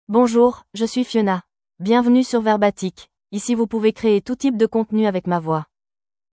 FemaleFrench (France)
FionaFemale French AI voice
Fiona is a female AI voice for French (France).
Voice sample
Fiona delivers clear pronunciation with authentic France French intonation, making your content sound professionally produced.